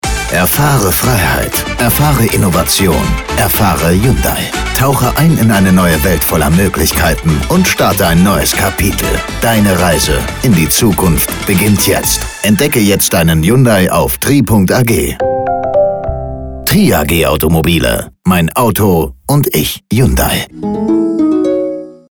Sound Logo im Audio Spot